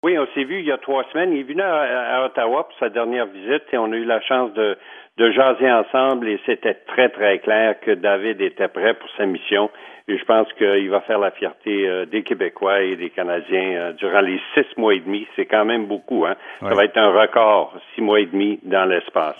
Alain Gravel entrevistó a Marc Garneau.